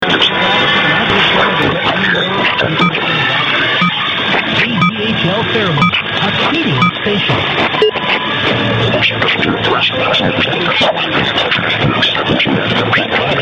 121029_0700_710_rebelde_also_1180_to_compare.mp3